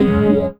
17CHORD03 -L.wav